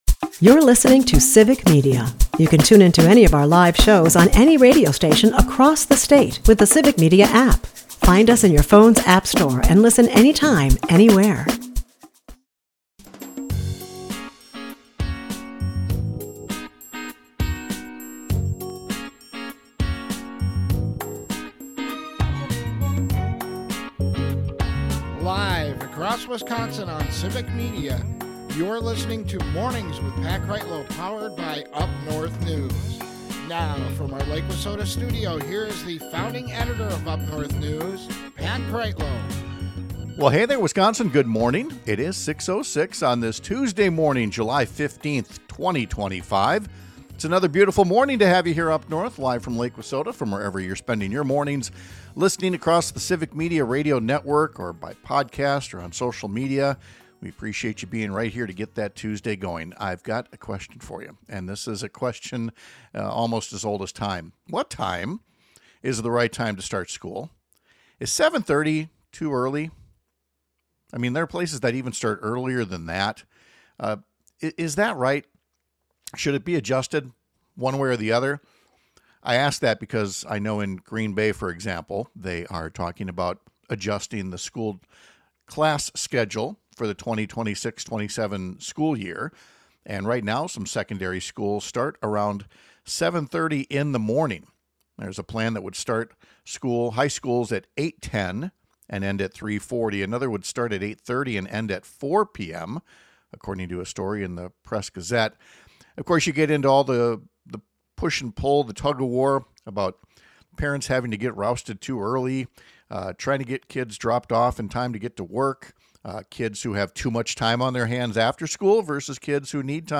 Washington County Executive Josh Schoemann stopped by the Civic Media booth at the Northern Wisconsin State Fair in Chippewa Falls last Friday to talk about his campaign for governor.